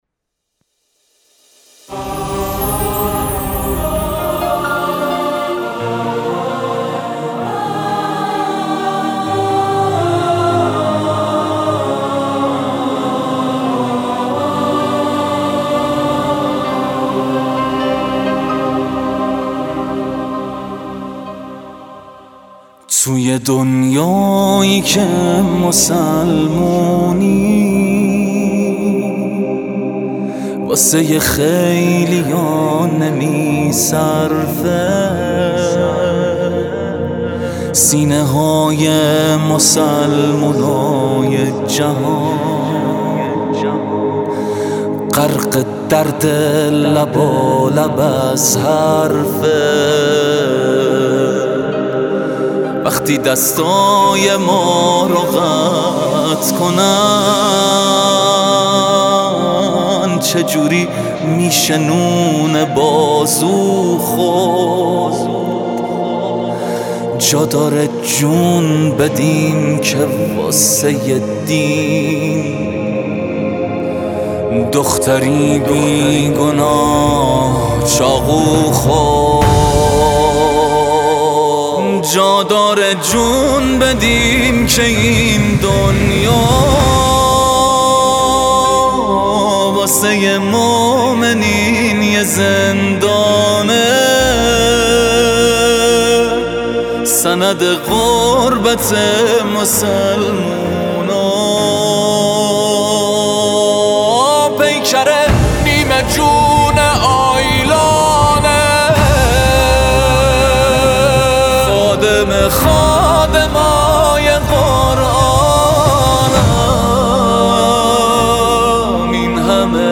حامد زمانی خوانند انقلابی کشورمان در سی و چهارمین دوره مسابقات بین‌المللی به اجرای قطعه‌ای با مضامین قرآنی پرداخت.
به گزارش خبرنگار فرهنگی باشگاه خبرنگاران پویا، حامد زمانی خوانند انقلابی کشورمان لحظاتی پیش با حضور در مصلای امام خمینی(ره) و در جمع شرکت‌کنندگان سی و چهارمین دوره مسابقات بین‌المللی قرآن کریم به اجرای قطعه‌ای با مضامین قرآنی پرداخت.